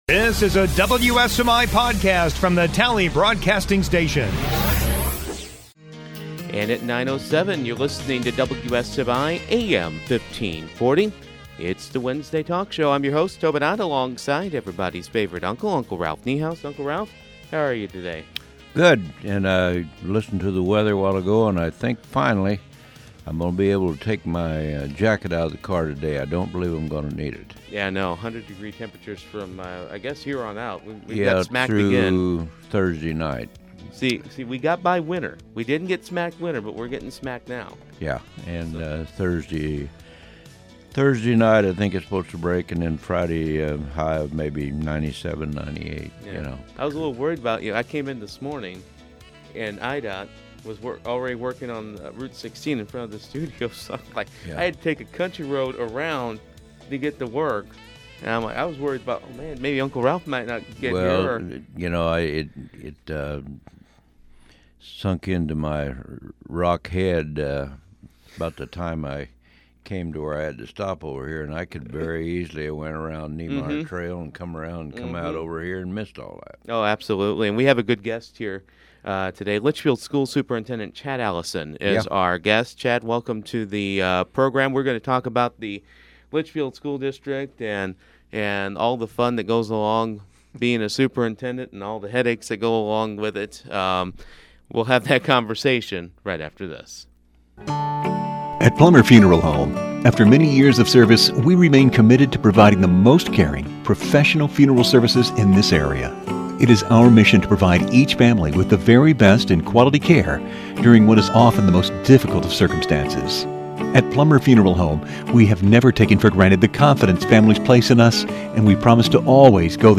The Wednesday Morning Talk Show